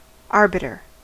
Ääntäminen
IPA : /ˈɑːbɪtə(r)/